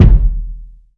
KICK.31.NEPT.wav